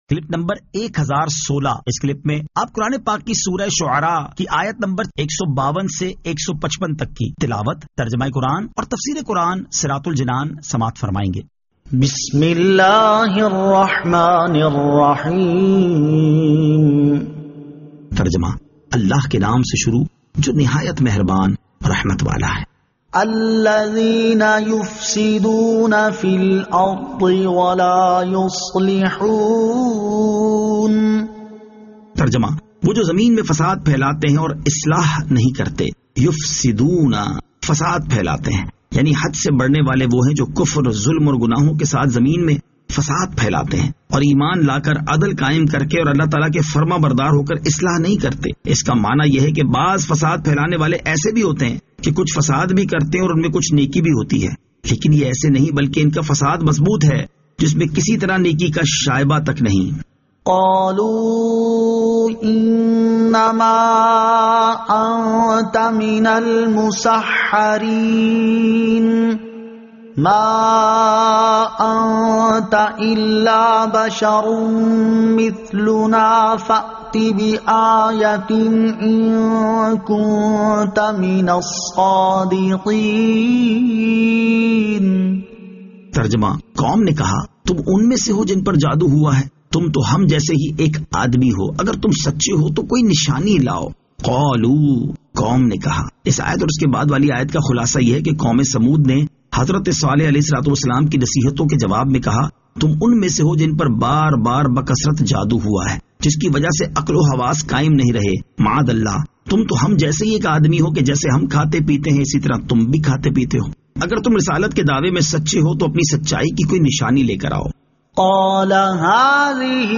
Surah Ash-Shu'ara 152 To 155 Tilawat , Tarjama , Tafseer